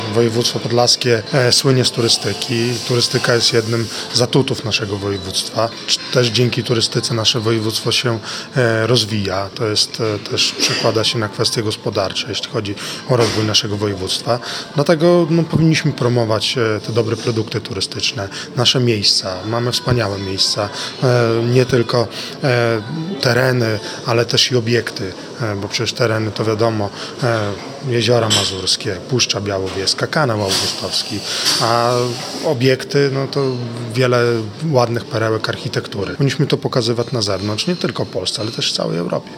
Nagrody i wyróżnienia wręczał Artur Kosicki, marszałek województwa podlaskiego.
marszałek-ok.mp3